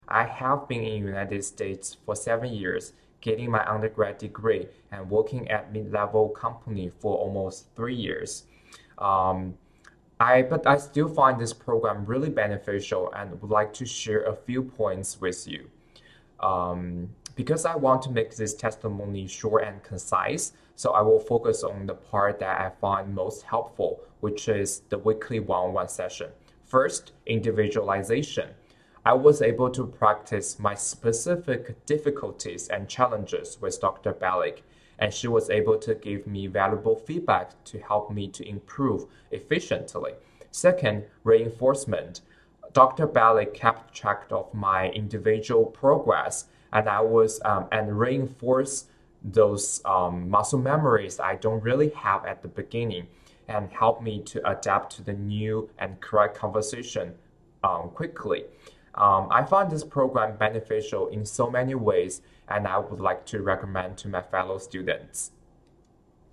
Testimonial 2